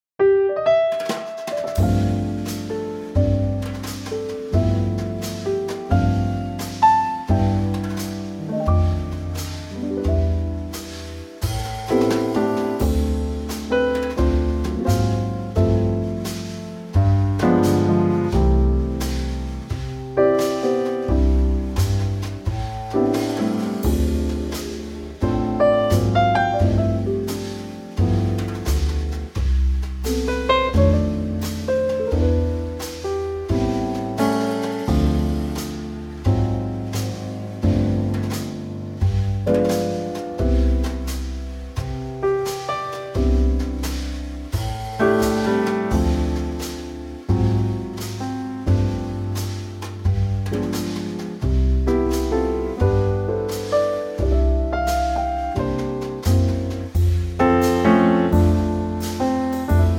Unique Backing Tracks
key - C - vocal range - G to B